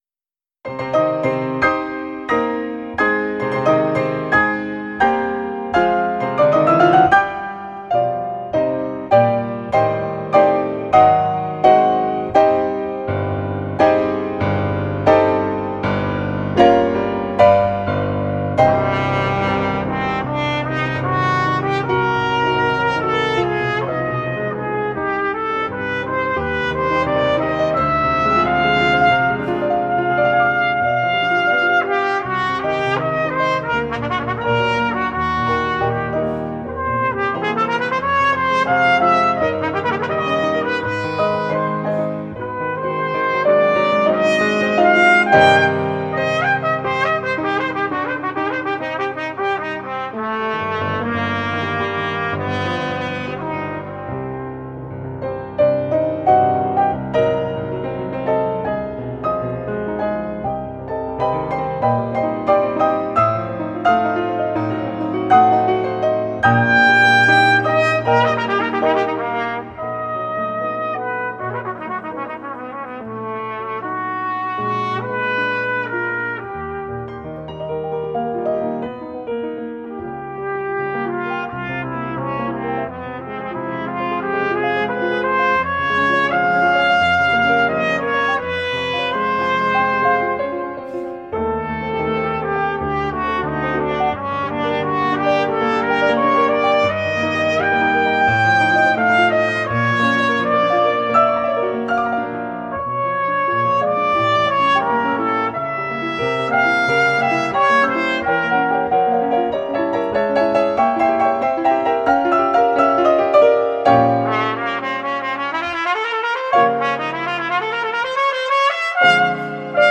Vladimir Peskin (1906-1988): Concerto no.1 in C minor for Trumpet and Piano.
trumpet.
piano.